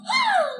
Catgirl Goes Haa
Play Catgirl Goes Haa Sound Button For Your Meme Soundboard!